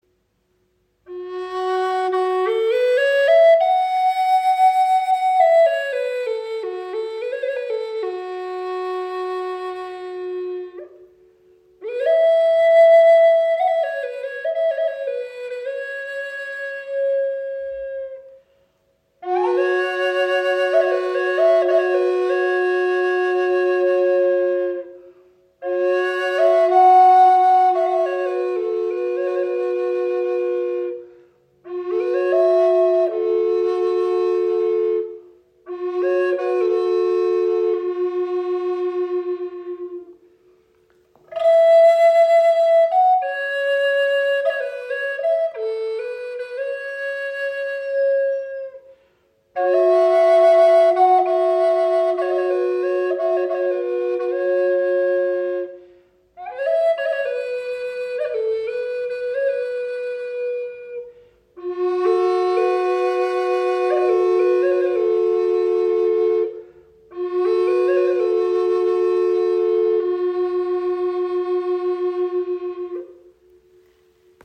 Doppelflöte in F# - 432 Hz im Raven-Spirit WebShop • Raven Spirit
Klangbeispiel
Sie schenkt Dir ein wundervolles Fibrato, kann als Soloinstrument gespielt werden oder als weiche Untermahlung Deiner Musik.